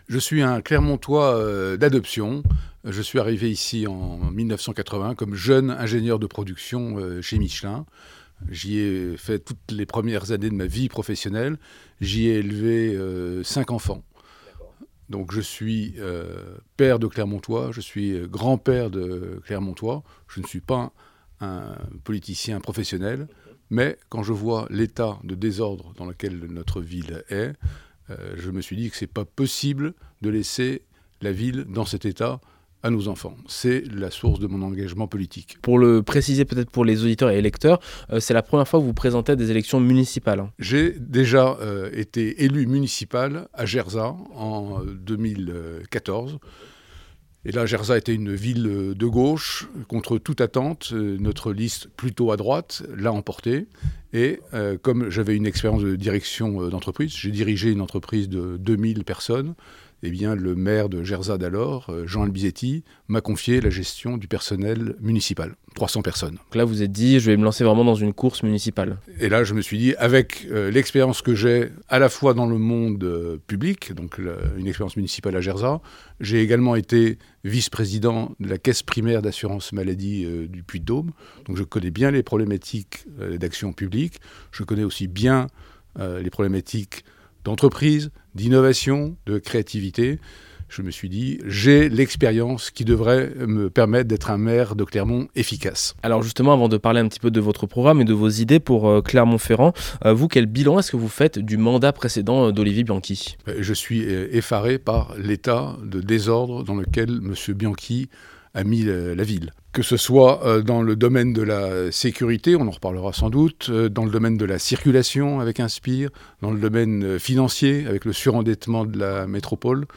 Ce dimanche 15 mars, c'est le premier tour des élections municipales à Clermont-Ferrand. Nous nous sommes entretenu avec les six candidats qui souhaitent briguer la mairie Clermontoise jusqu'en 2033.